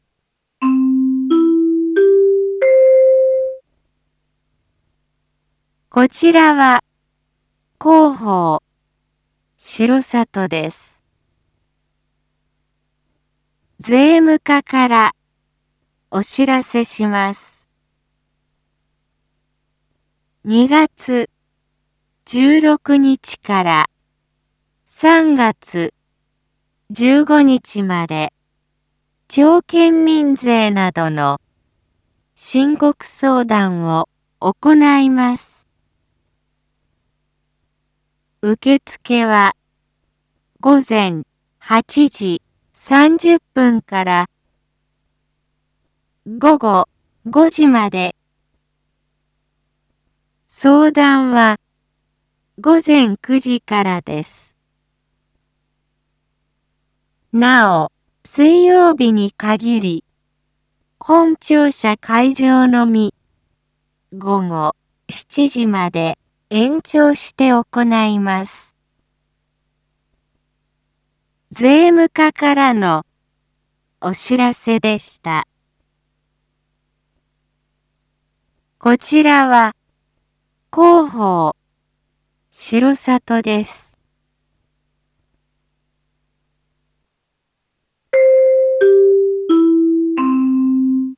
一般放送情報
Back Home 一般放送情報 音声放送 再生 一般放送情報 登録日時：2022-03-02 07:01:33 タイトル：申告相談のご案内 インフォメーション：こちらは広報しろさとです。